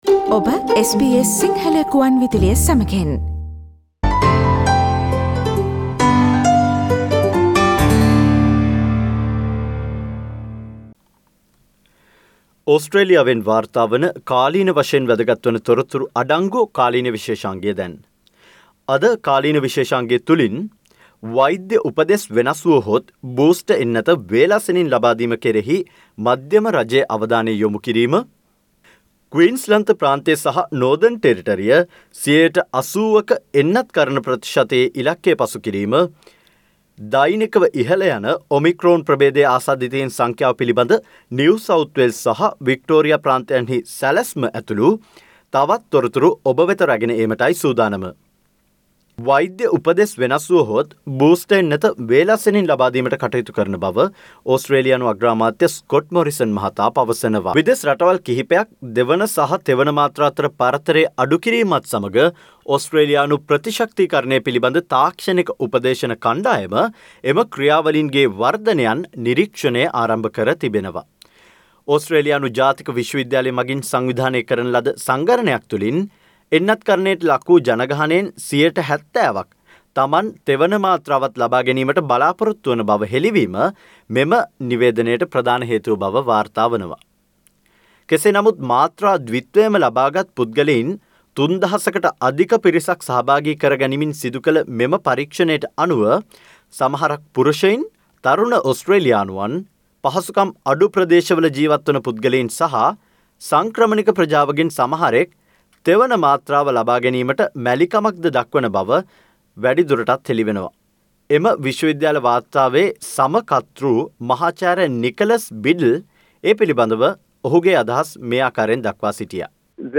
Brining you the latest COVID-19 updated around Australia - broadcasted on 10 December 2021